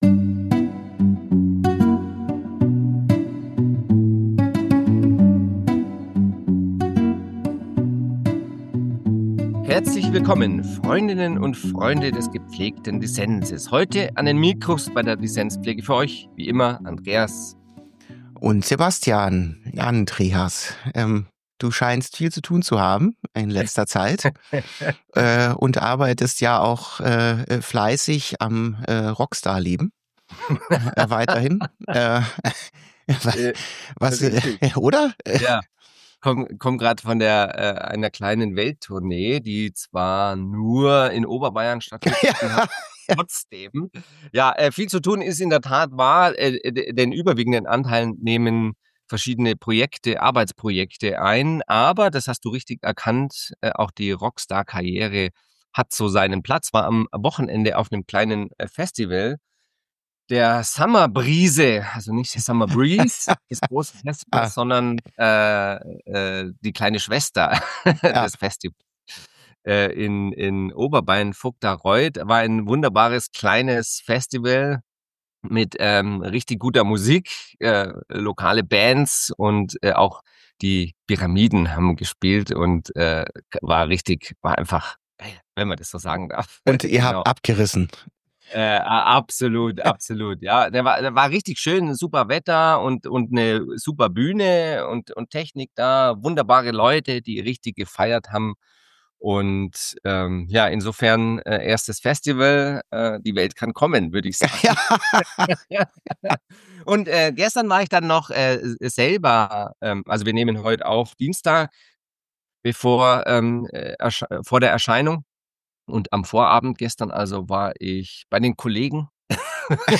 Doch ist es eigentlich immer und in allen Fällen falsch, wenn ein Land einem anderen den Krieg erklärt? Gibt es möglicherweise gute Gründe für den Griff zu den Waffen? Darüber diskutieren die beiden Dissenspfleger in der heutigen Folge.